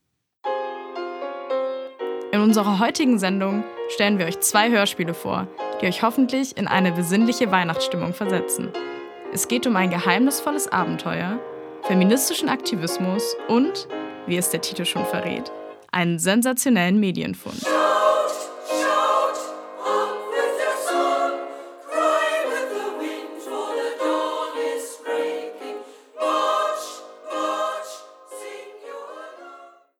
In unserer heutigen Sendung präsentieren wir zwei Hörspiele.
Musik: Tübinger Frauenchor
Seit ein paar Jahren haben wir im Sommersemester Gelegenheit, im historischen Hörspielstudio des SWR auf dem Tübinger Österberg mit Masterstudierenden der Medienwissenschaft selbst verfasste Kurzhörspiele aufzunehmen.